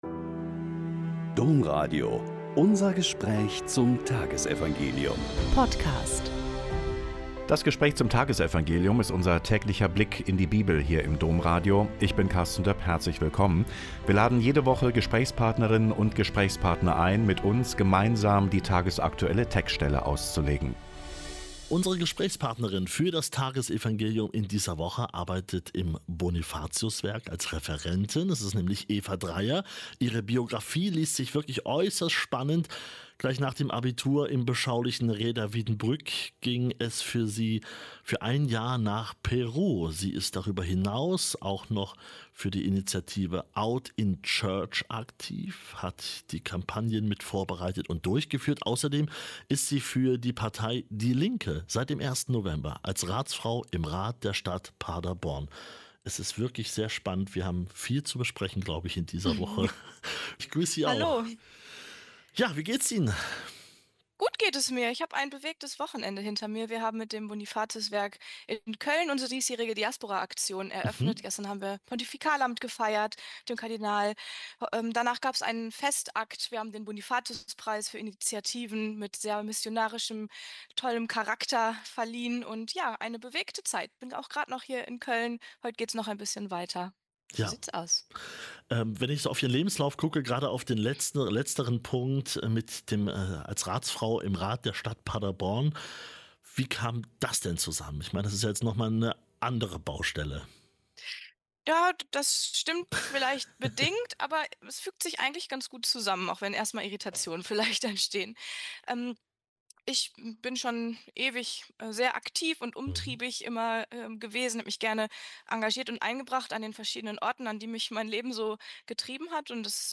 Lk 17,1-6 - Gespräch